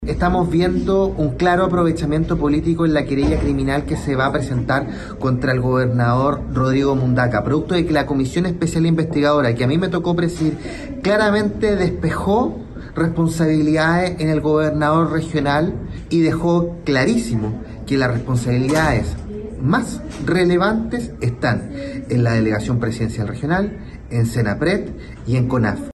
Sobre esta querella criminal, el diputado Tomás Lagomarsino, quien presidió la comisión investigadora del megaincendio, aseguró que hay un aprovechamiento político y que la responsabilidad no debe caer sobre el gobernador regional, Rodrigo Mundaca.